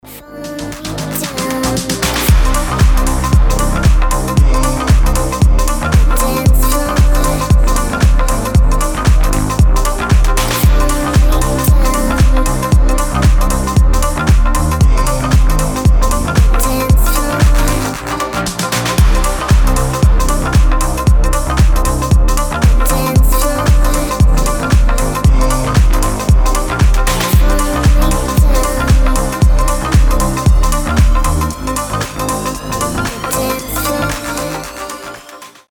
• Качество: 320, Stereo
deep house
атмосферные
Club House
Vocal House
Стиль: deep, vocal, club house